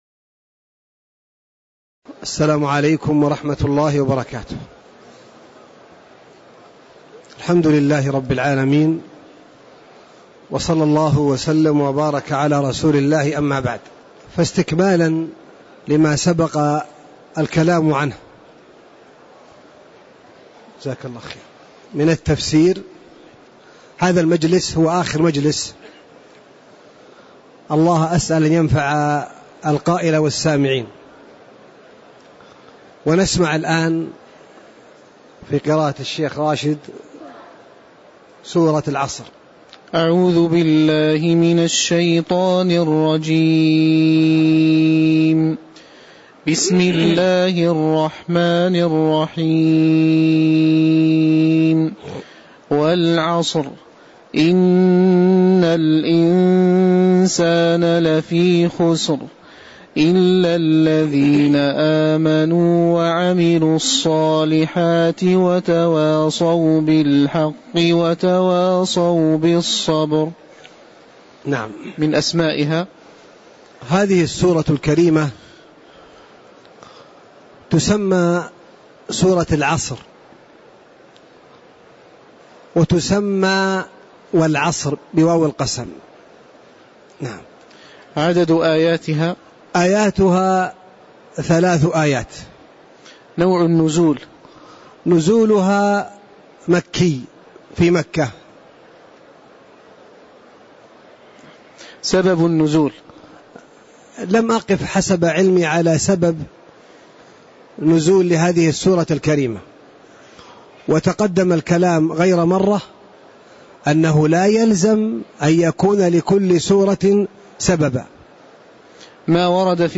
تاريخ النشر ٨ جمادى الآخرة ١٤٣٧ هـ المكان: المسجد النبوي الشيخ